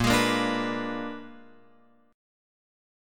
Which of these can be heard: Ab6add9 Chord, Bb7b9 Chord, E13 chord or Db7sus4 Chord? Bb7b9 Chord